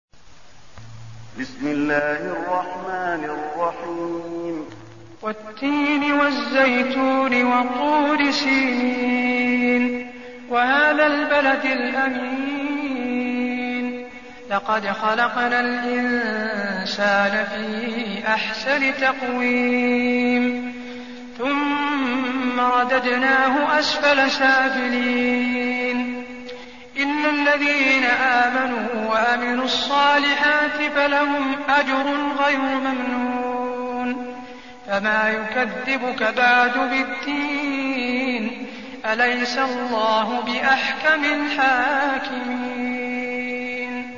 المكان: المسجد النبوي التين